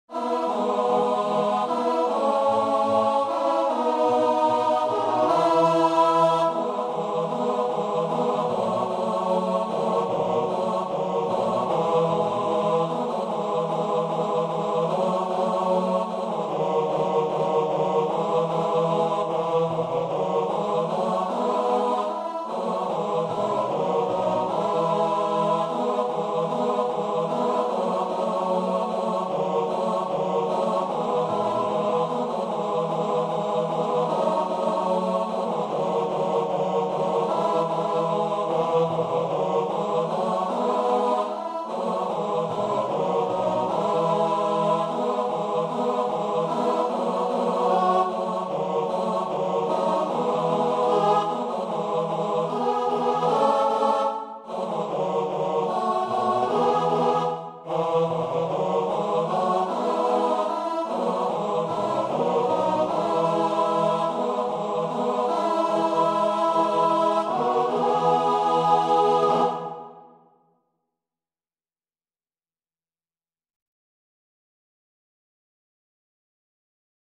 TBarB (3 voix égales d'hommes) ; Partition complète.
Tonalité : mi majeur